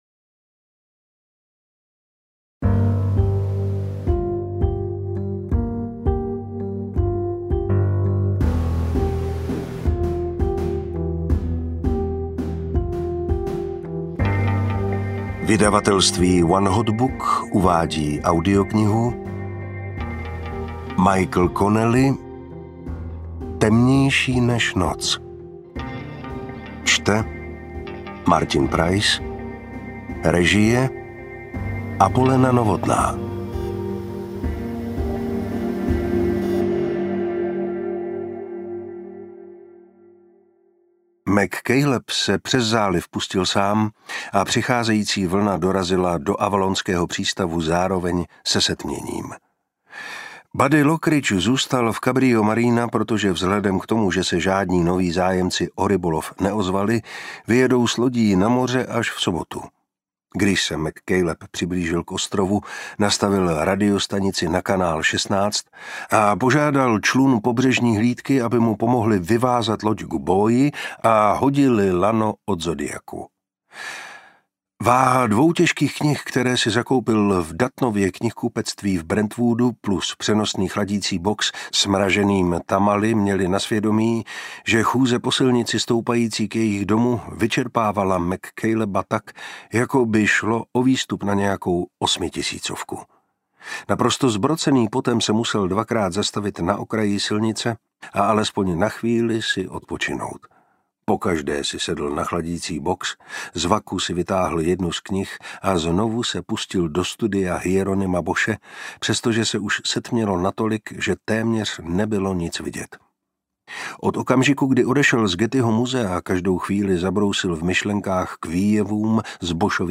Ukázka z knihy
• InterpretMartin Preiss